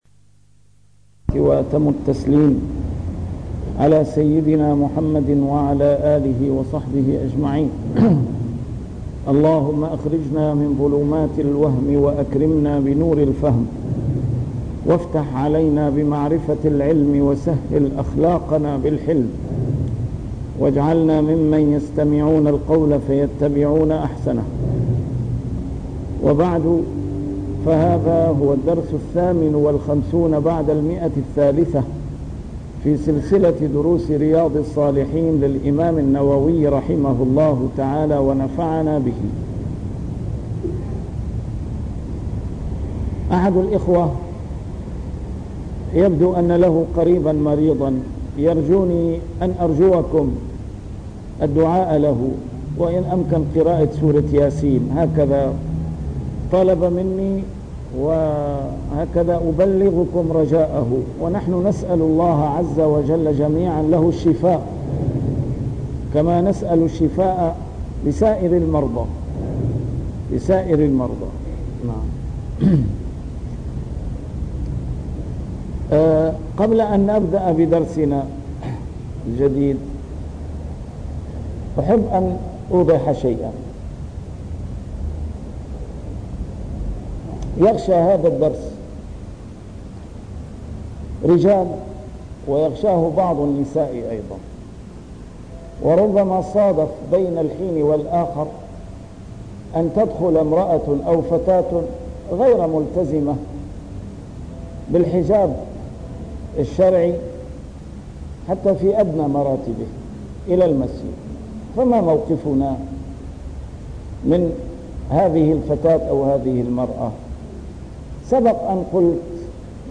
نسيم الشام › A MARTYR SCHOLAR: IMAM MUHAMMAD SAEED RAMADAN AL-BOUTI - الدروس العلمية - شرح كتاب رياض الصالحين - 358- شرح رياض الصالحين: الإصلاح بين الناس